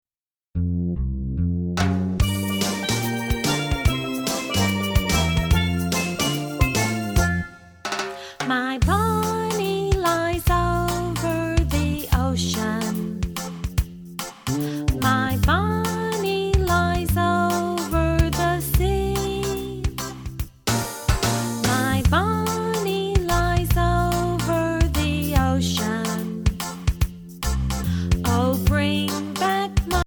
children's favorite play-songs